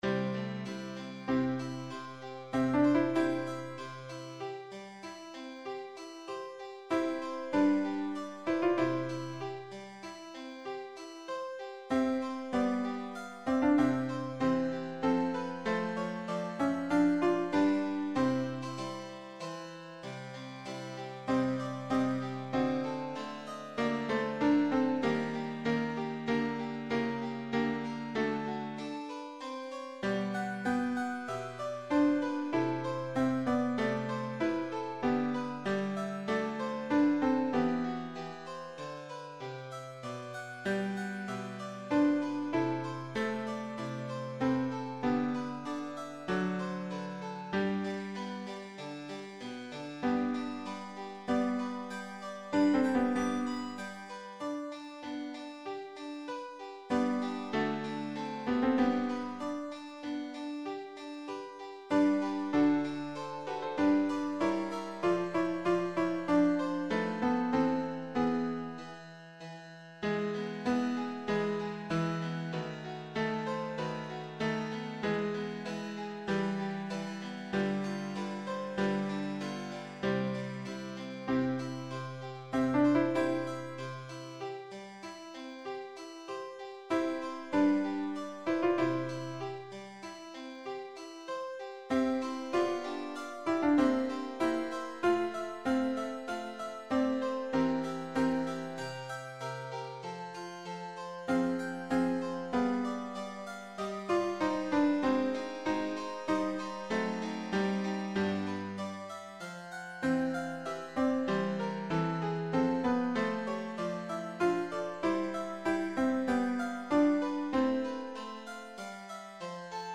Sans paroles
Voix + chœur en sourdine